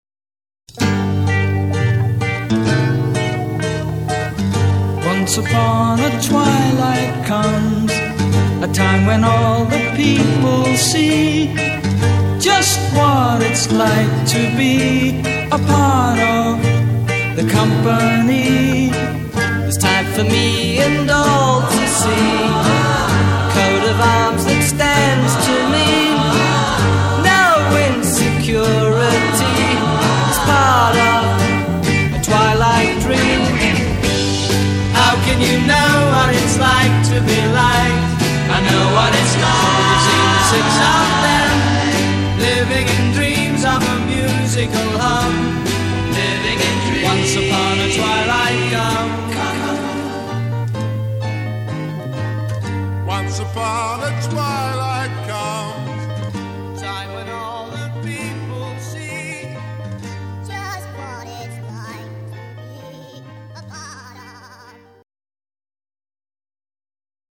blues-rock band our country has ever known